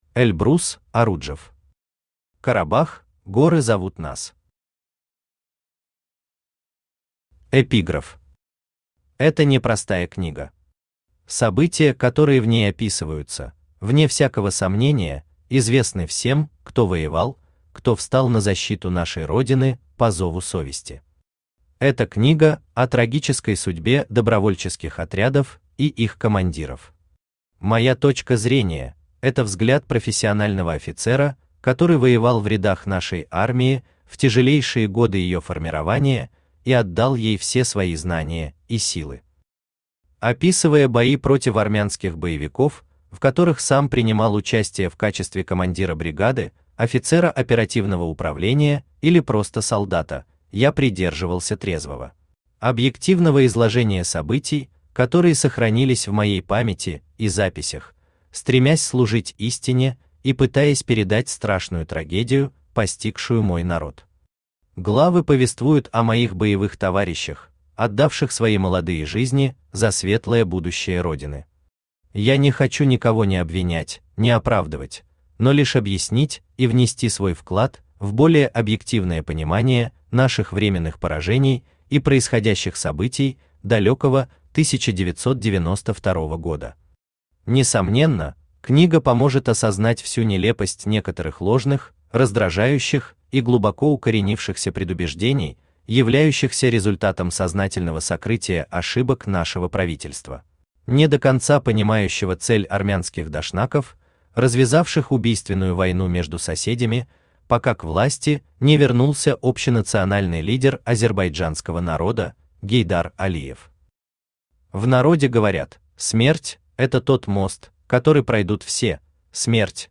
Аудиокнига Карабах – горы зовут нас | Библиотека аудиокниг
Aудиокнига Карабах – горы зовут нас Автор Эльбрус Иззят оглы Оруджев Читает аудиокнигу Авточтец ЛитРес.